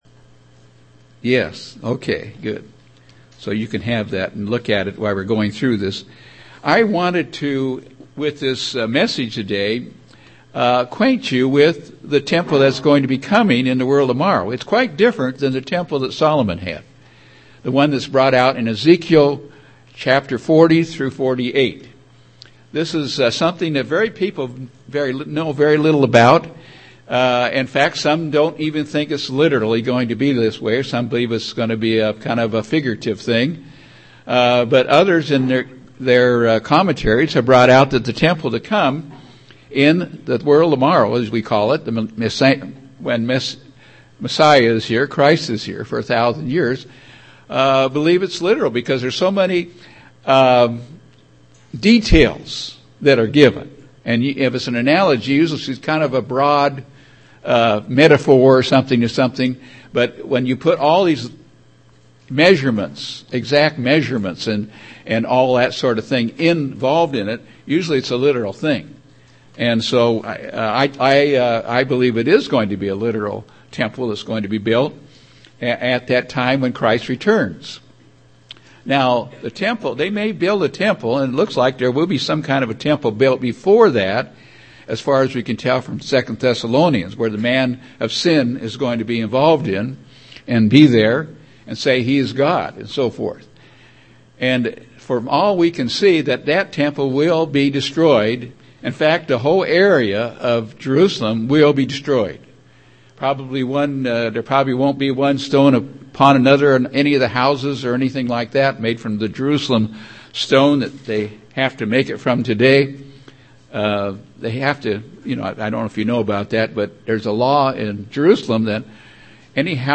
5-12-12 Sermon.mp3